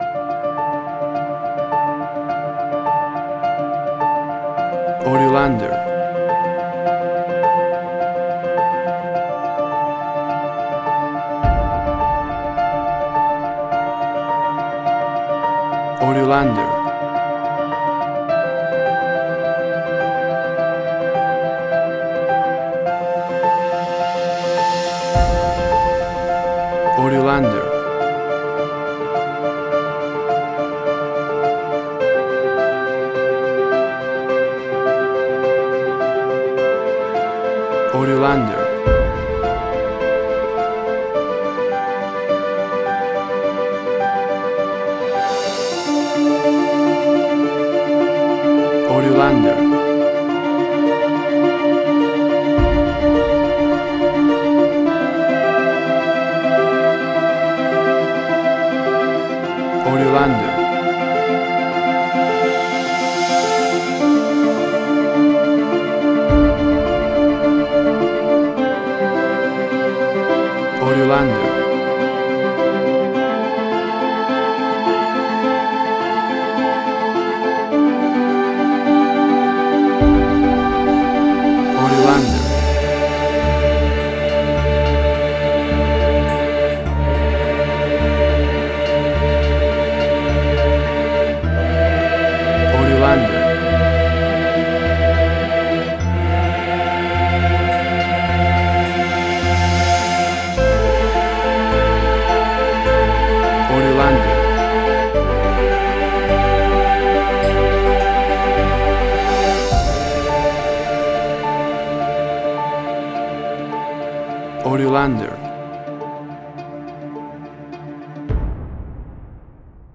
Suspense, Drama, Quirky, Emotional.
WAV Sample Rate: 16-Bit stereo, 44.1 kHz
Tempo (BPM): 105